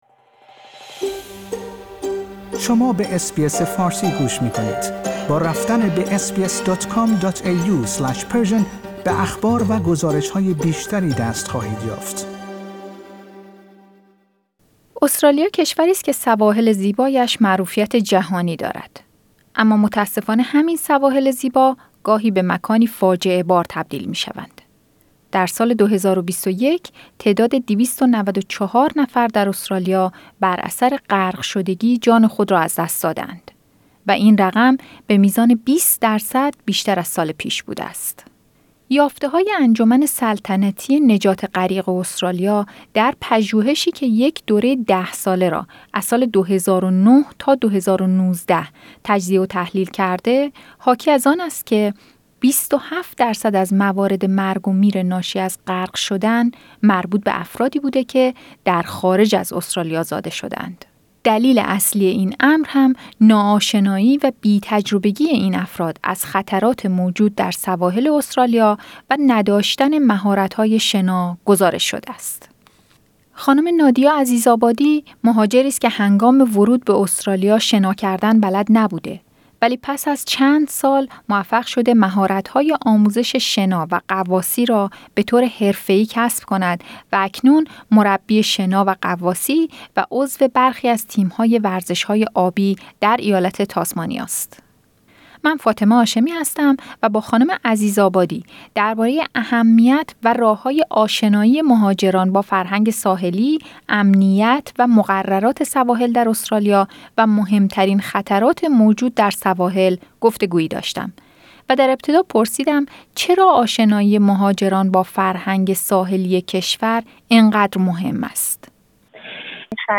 یافته‌های انجمن سلطنتی نجات غریق استرالیا در پژوهشی که یک دوره ده ساله را از ۲۰۰۹ تا ۲۰۱۹ تجزیه و تحلیل کرده حاکی از آن است که ۲۷ درصد از موارد مرگ و میر ناشی از غرق شدن مربوط به افرادی بوده است که در خارج از استرالیا زاده شده‌اند. گفتگویی داشتیم